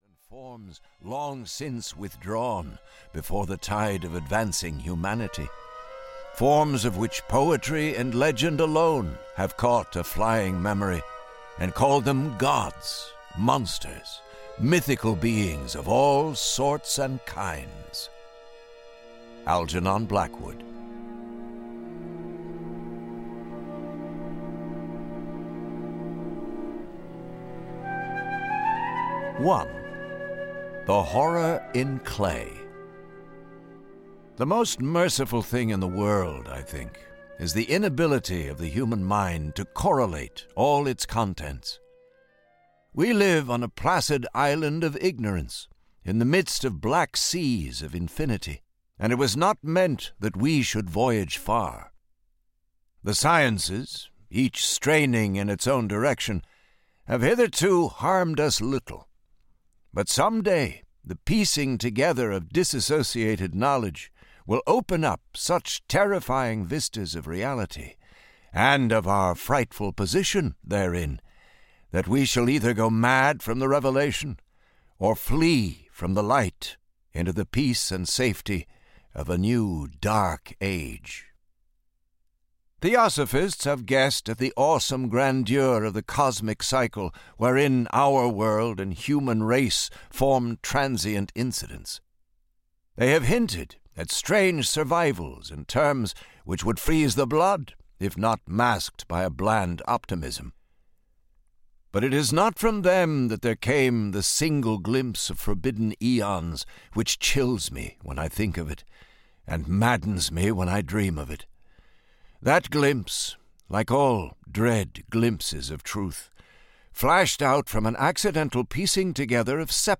The Call of Cthulhu and Other Stories (EN) audiokniha
Ukázka z knihy